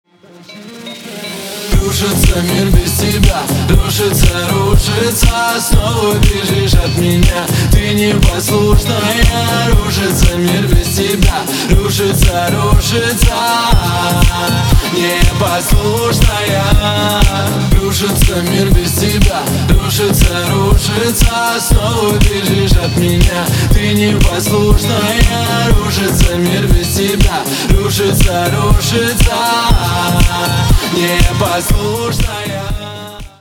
• Качество: 320, Stereo
поп
dance
электронная музыка